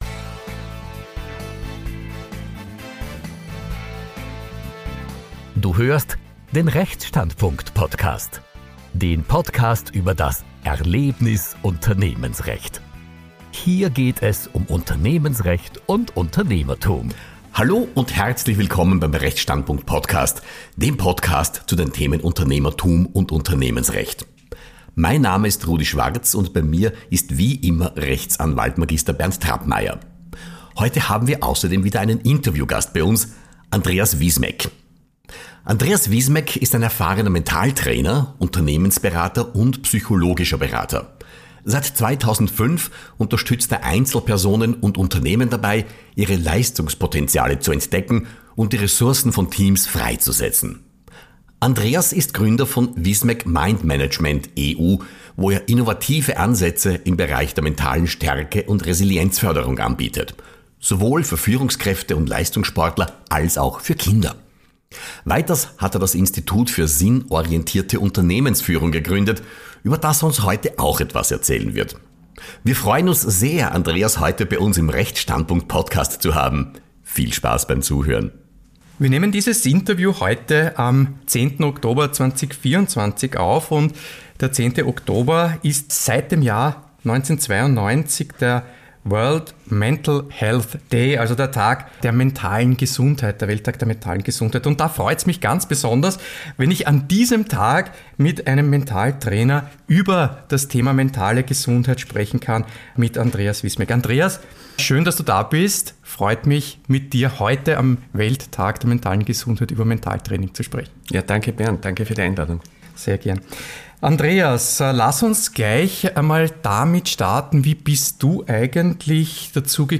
im Interview ~ Rechtsstandpunkt Podcast